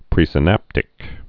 (prēsĭ-năptĭk)